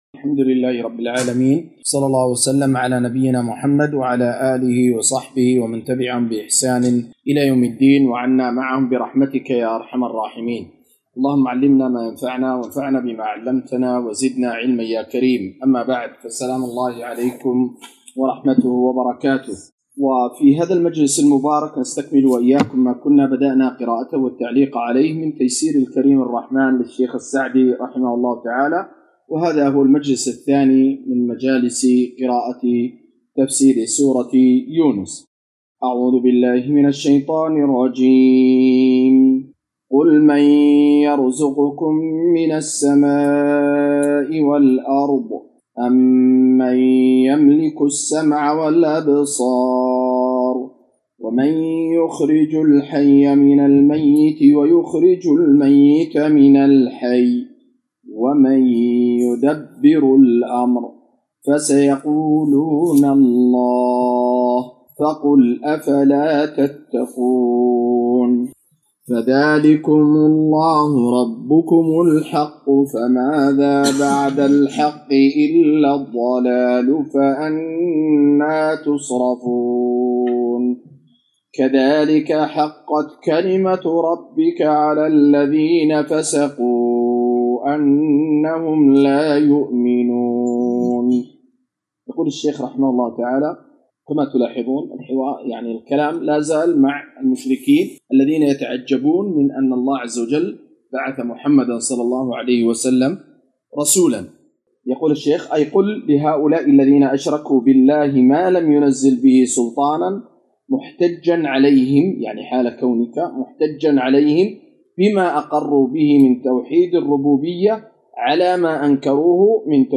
قراءة وتعليق